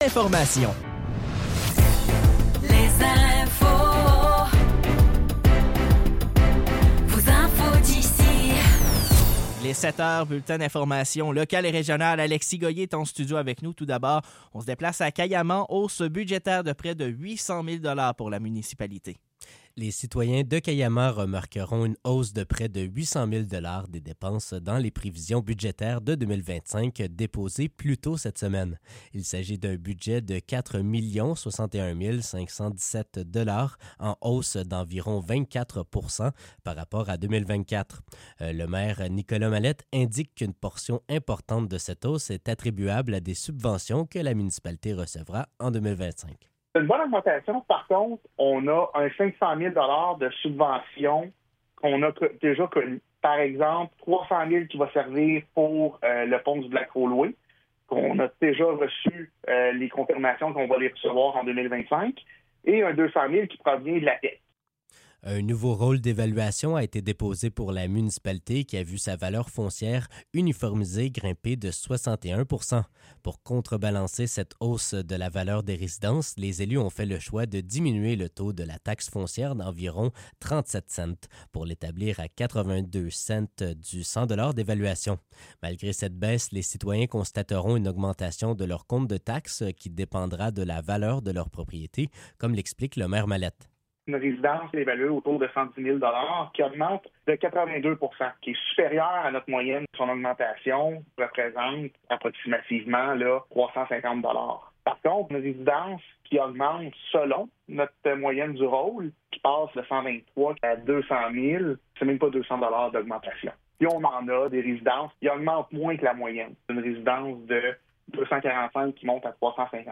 Nouvelles locales - 20 décembre 2024 - 7 h